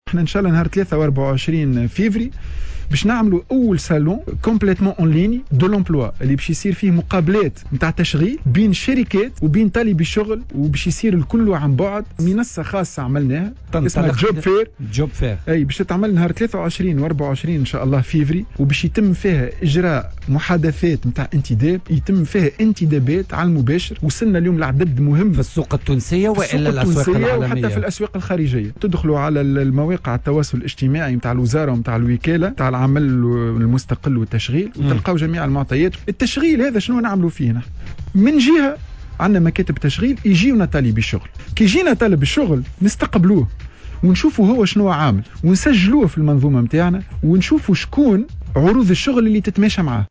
وأضاف في مداخلة له اليوم على "الجوهرة أف أم" أنه سيتم إجراء محادثات انتداب عن بعد عبر منصة رقمية خاصة وإتاحة الفرصة للباحثين عن فرص عمل للتواصل مع أصحاب الشركات والمؤسسات الناشطة في السوق التونسية أو في الخارج.